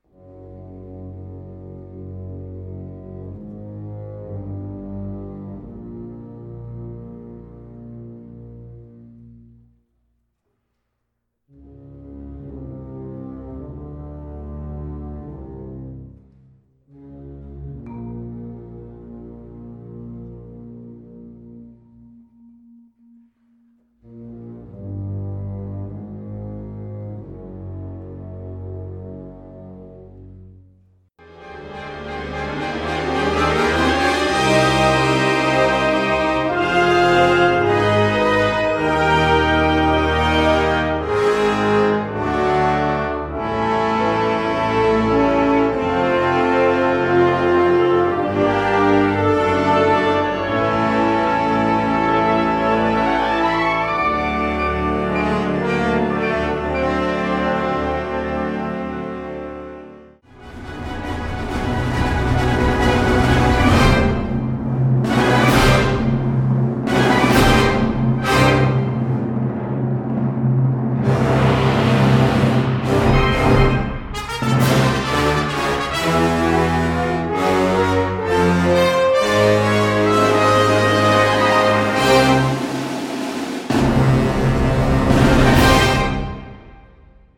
Kategorie Blasorchester/HaFaBra
Unterkategorie Zeitgenössische Bläsermusik (1945-heute)
für sinfonisches Blasorchester